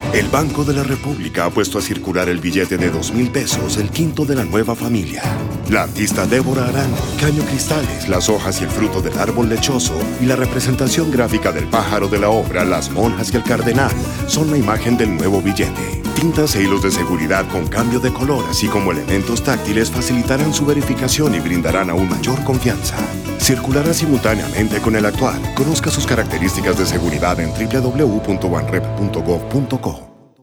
Cuña radial